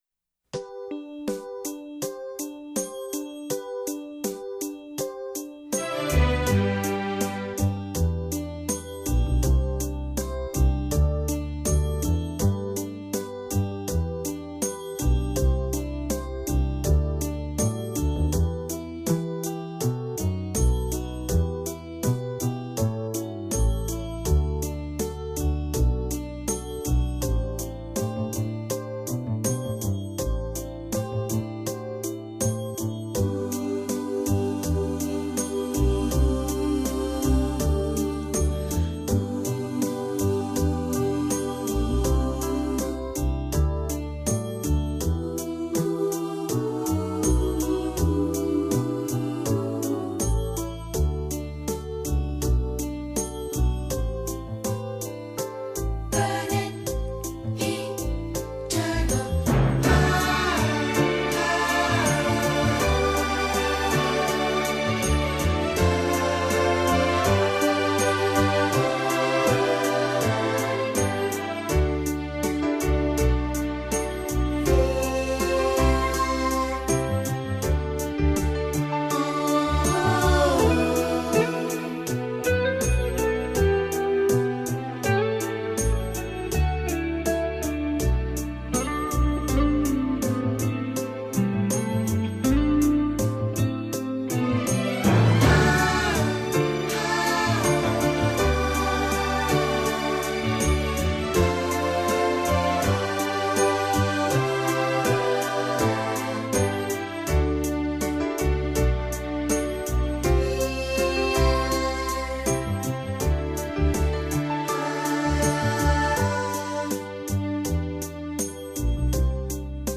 Karaoke Version